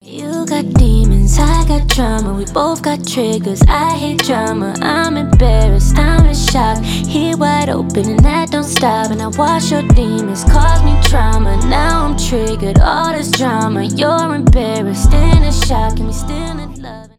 rnb , романтические
поп